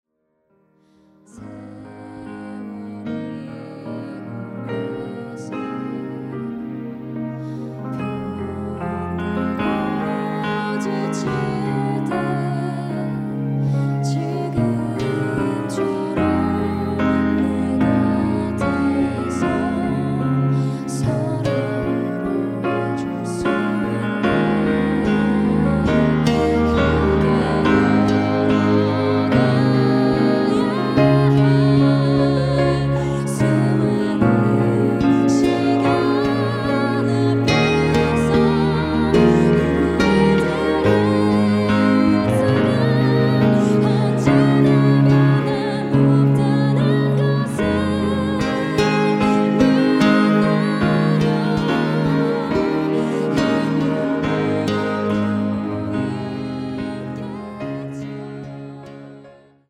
음정 원키 4:17
장르 가요 구분 Voice Cut
Voice Cut MR은 원곡에서 메인보컬만 제거한 버전입니다.